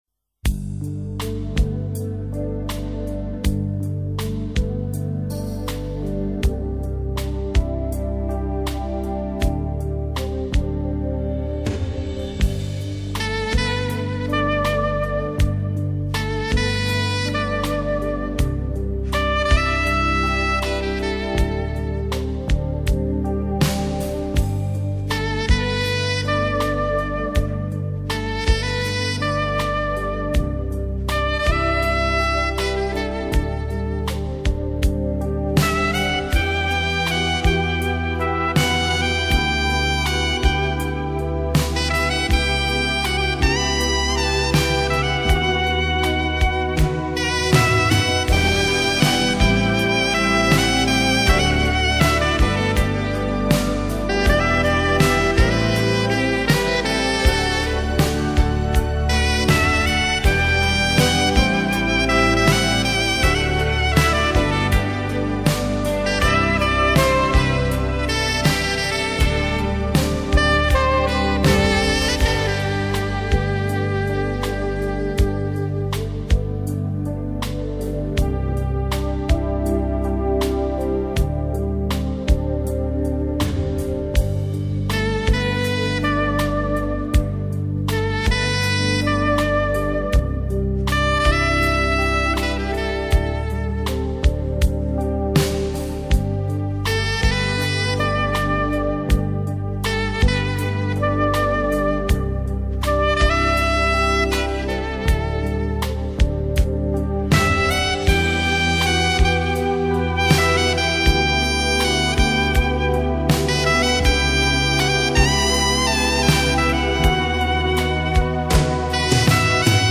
Грустный СакС
Grustnyq-SakS.mp3